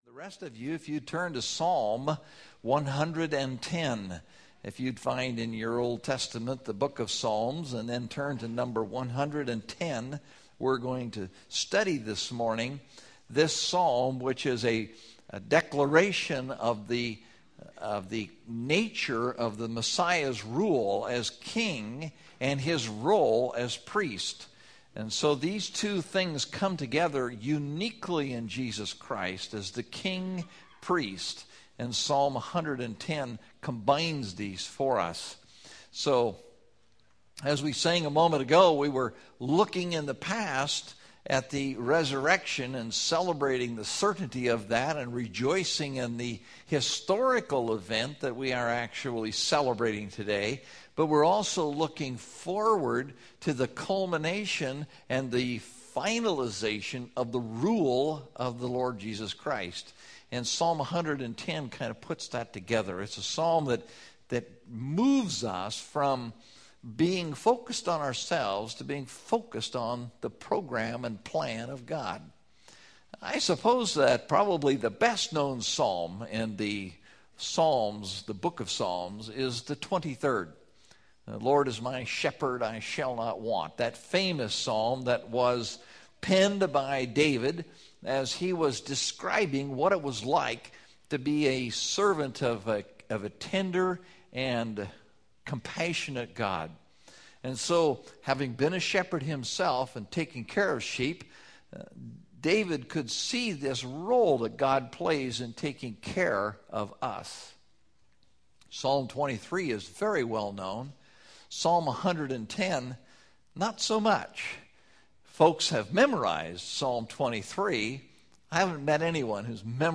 Topical Message